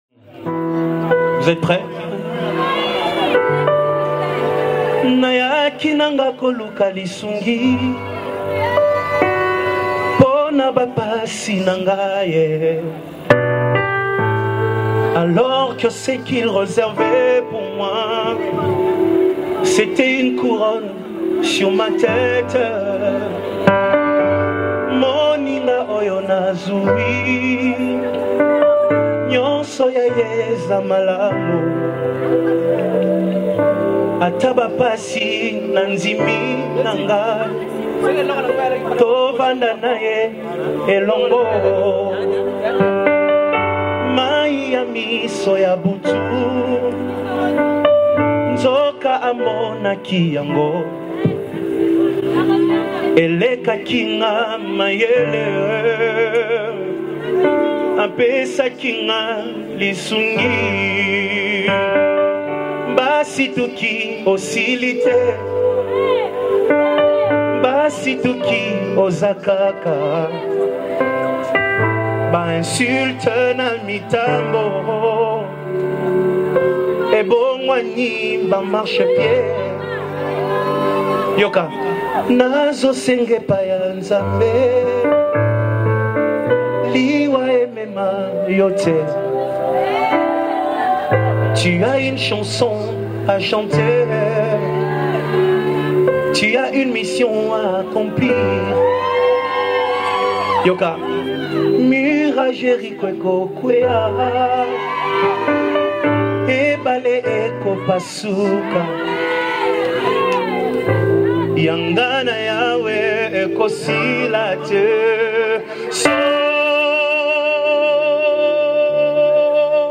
Congo Gospel Music